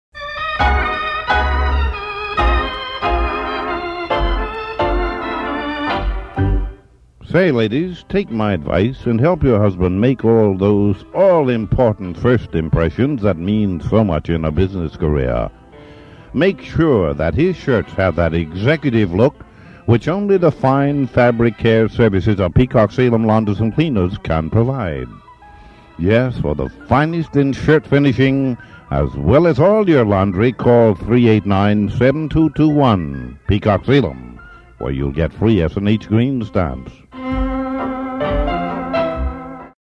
Commercials
Most were produced by WROV personalities in the WROV production room, but we've included a few other famous ones along with them.